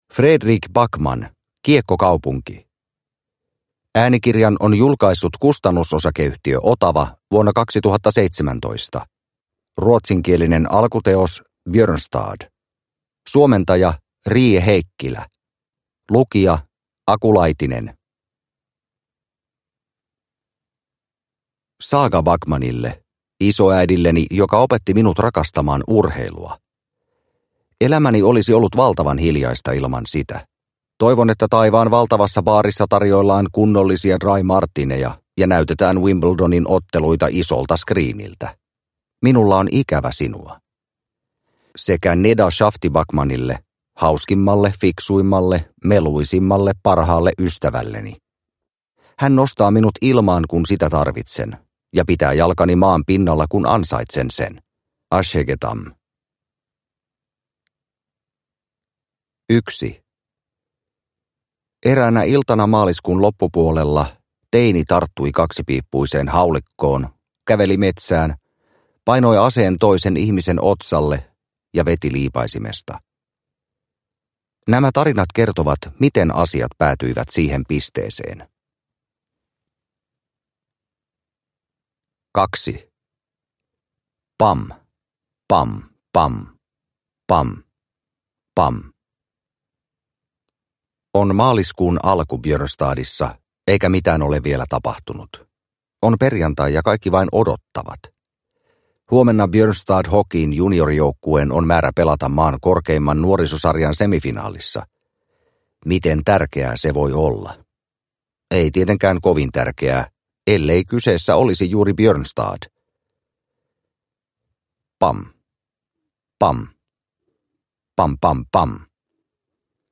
Kiekkokaupunki – Ljudbok – Laddas ner